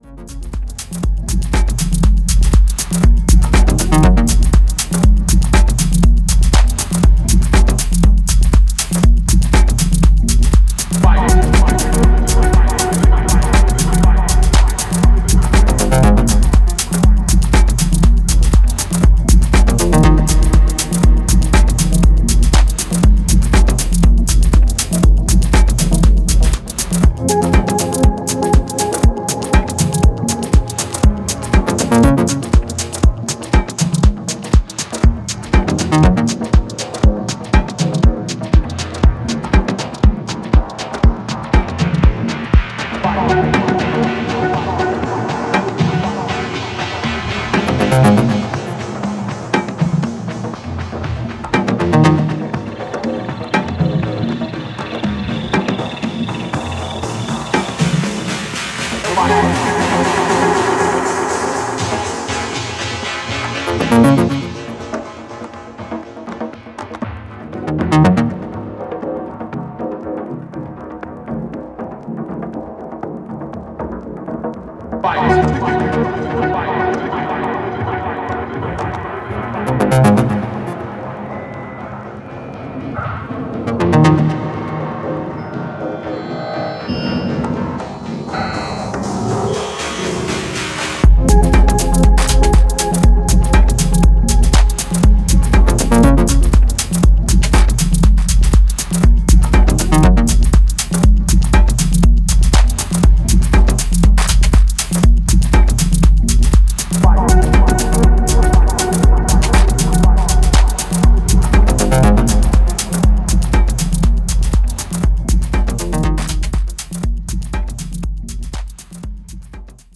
Style: Techno / Deep - Experimental - Minimal